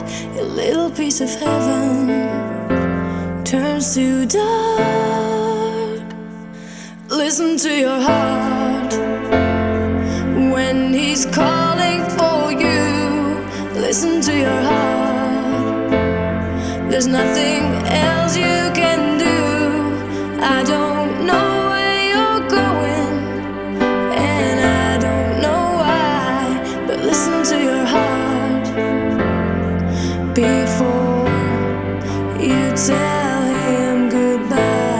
Жанр: Поп музыка / Рок / Танцевальные